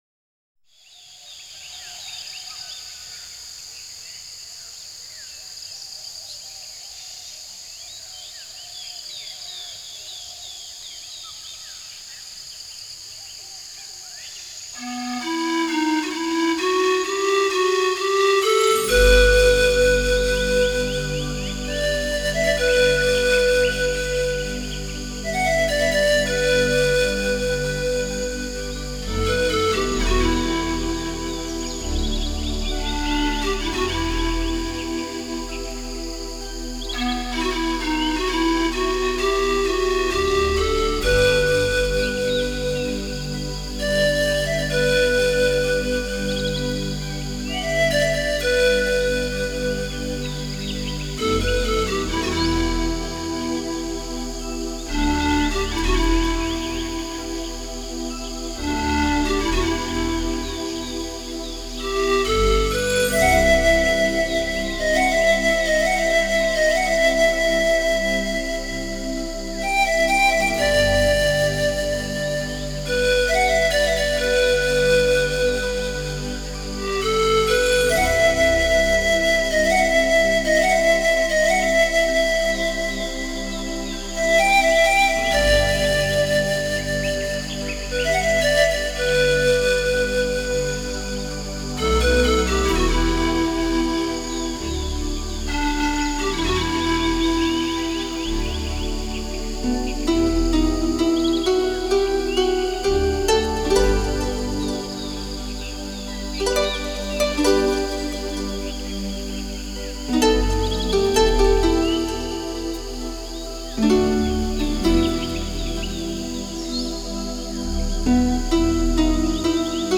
给你瑞士空灵飘渺的音乐世界